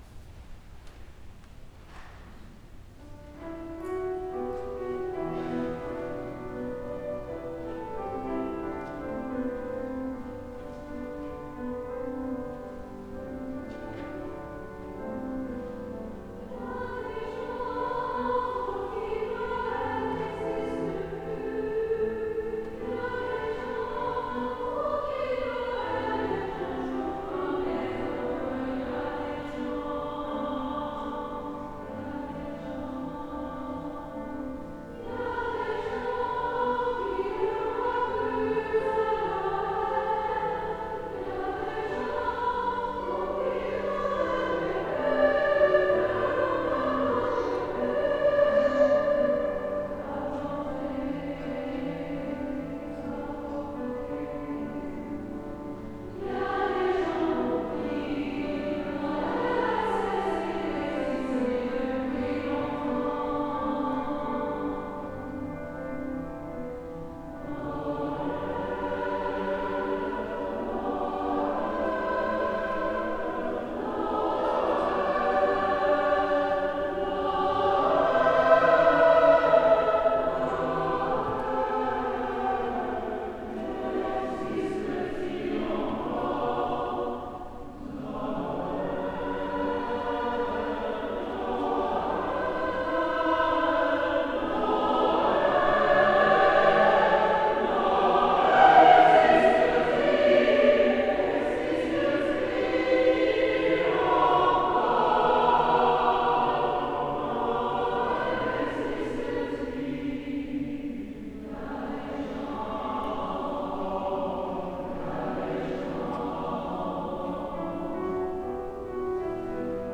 Ecoutez | Chorale de l'Université d'Ottawa
Ottawa, décembre 2024